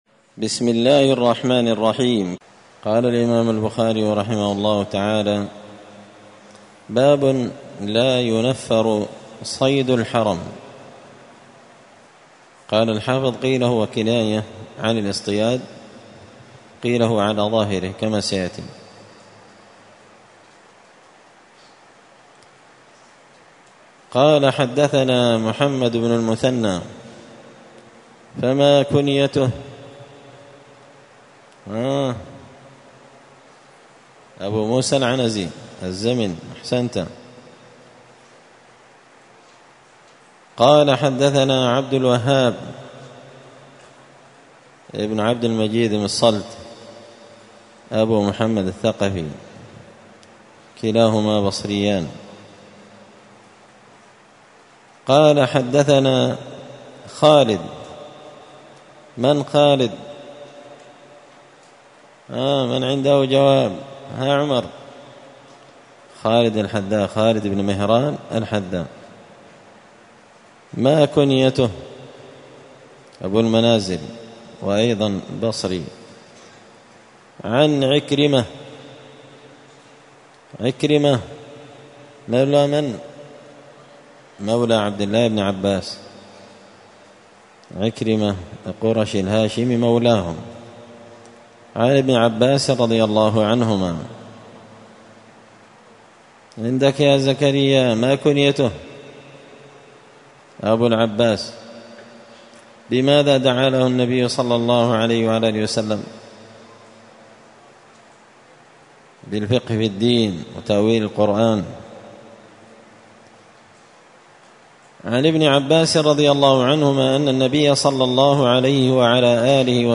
مسجد الفرقان قشن المهرة اليمن